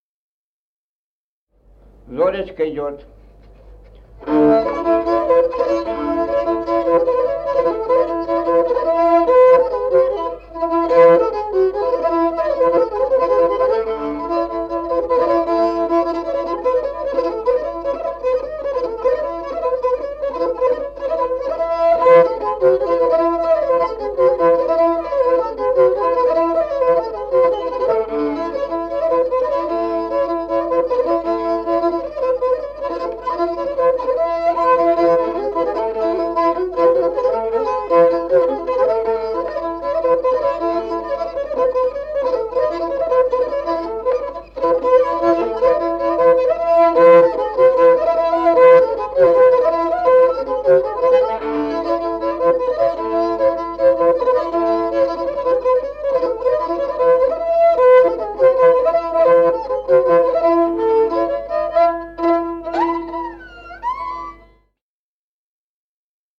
Музыкальный фольклор села Мишковка «Зоречка», репертуар скрипача.